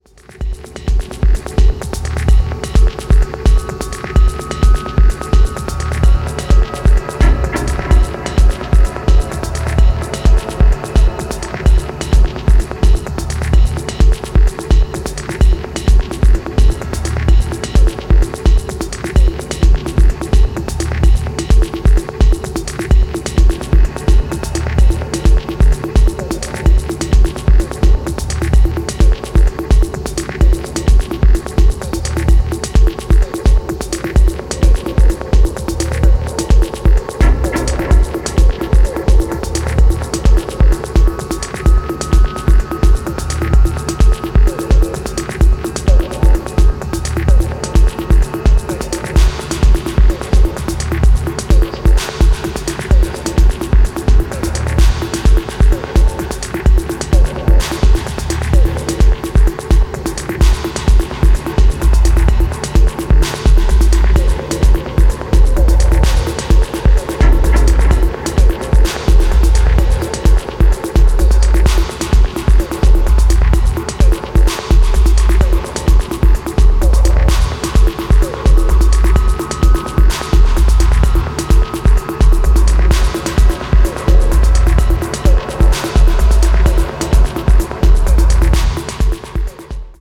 トランシーな127BPMブロークン・テクノ
いずれも極めてディープな存在感を放つトラック。”